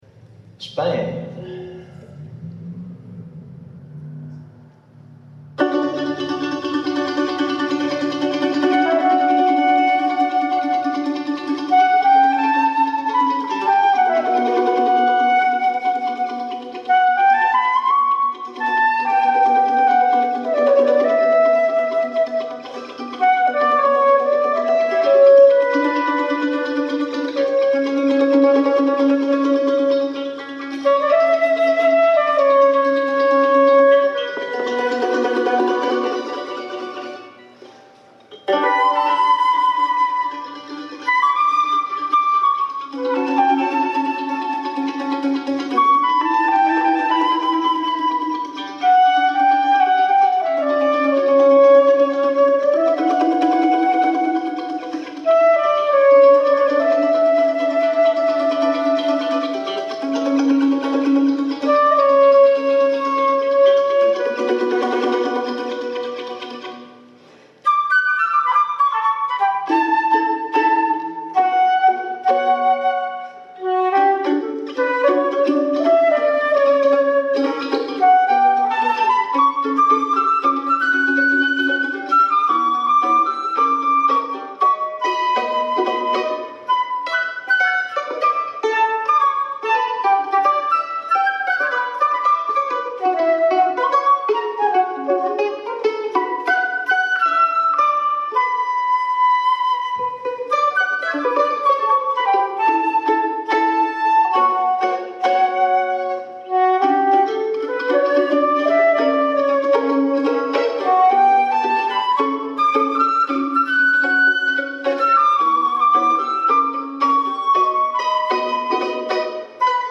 vocals, guitar, mandolin
vocals, guitar, flute, bass